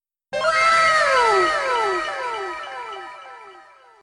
wow.mp3